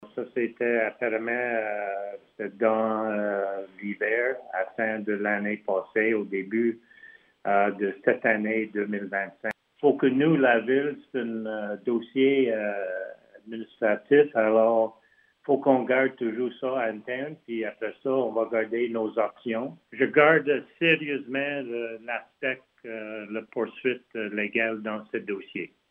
Le maire de Lac-Brome, Richard Burcombe, a fait une déclaration en début de séance annonçant qu’une enquête était en cours.
Écoutons Richard Burcombe :